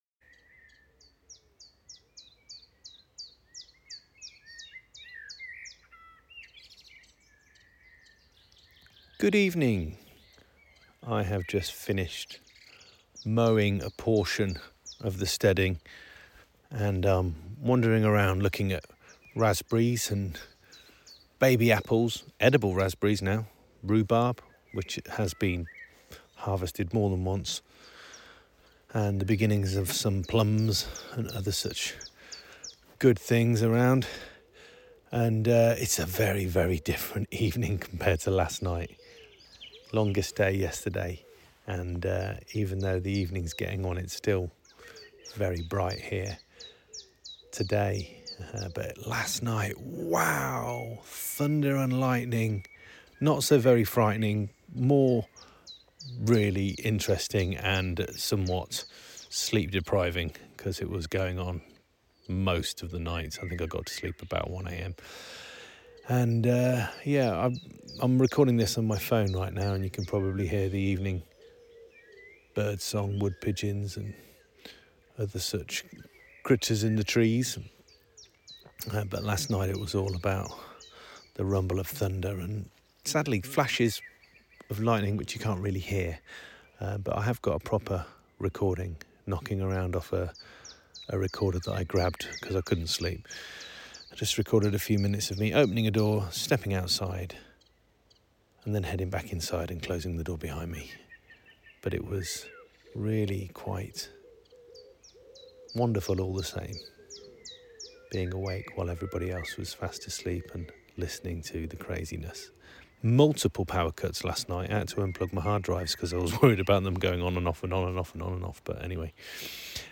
Standing in the garden 20 hours after a storm.